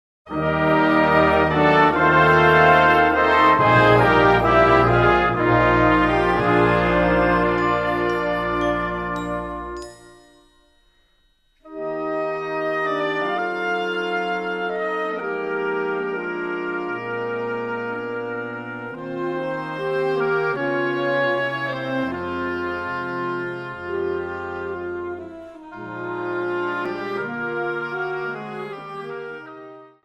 Smooth jazz
Swing